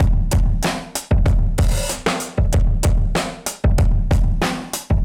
Index of /musicradar/dusty-funk-samples/Beats/95bpm/Alt Sound
DF_BeatB[dustier]_95-02.wav